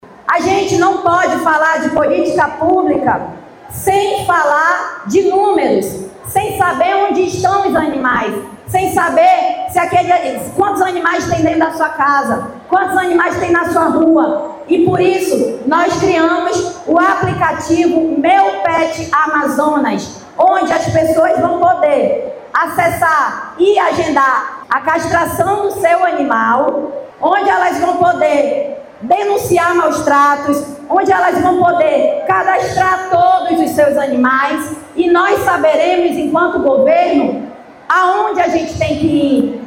A ferramenta conta, também, com funcionalidades voltadas à proteção animal, como canal para denúncias de maus-tratos e espaço para divulgação de animais disponíveis para adoção, como destaca a secretária da Secretaria de Estado de Proteção Animal (Sepet), Joana Darc.